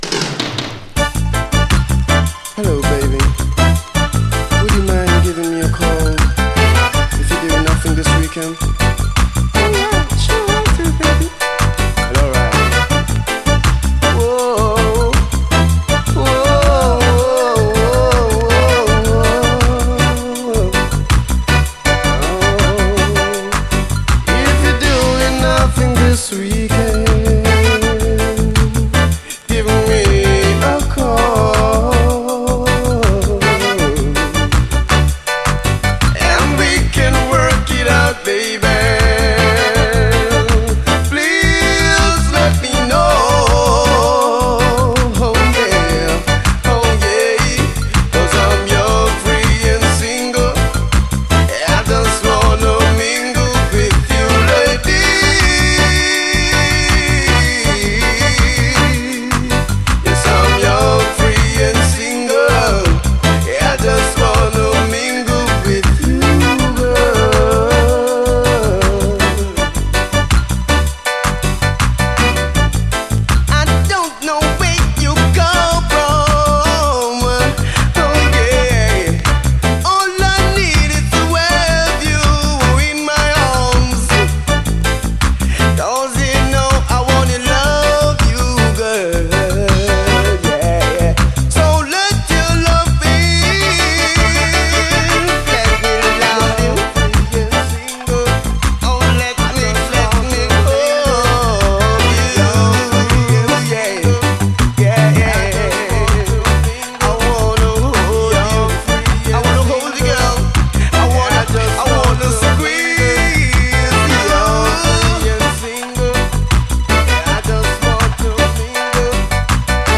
REGGAE
こちらは、90’Sらしいシンセ・サウンドが軽やか＆爽やかなナイス・ダンスホール・カヴァー！